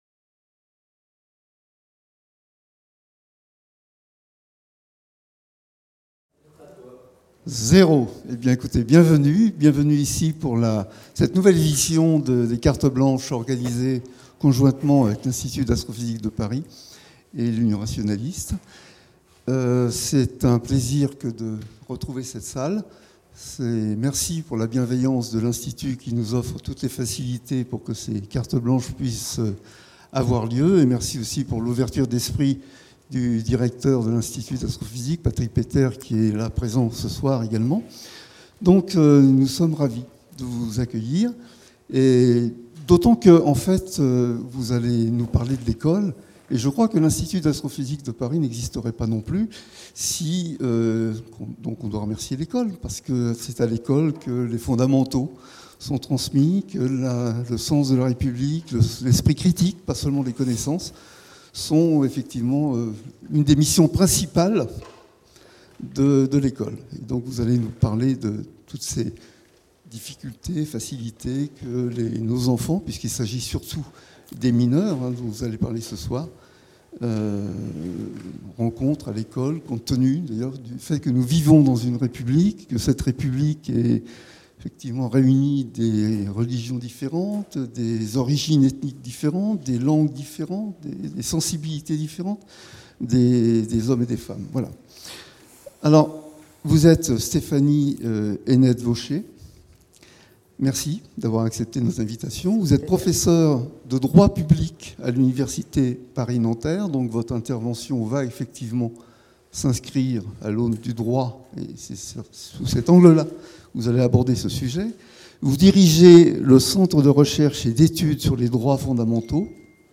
Cette conférence « Carte blanche à… » est proposée par l'Union rationaliste